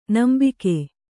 ♪ nambike